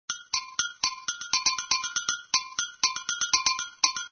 Index of /Habbo_Archive_V2_Wendigo/HabboStuff/Cokestudios Private Server/Cokemusic/src/Mixer Library/Latin Sounds
shared_latinoBells00.mp3